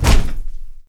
FEETS 2   -R.wav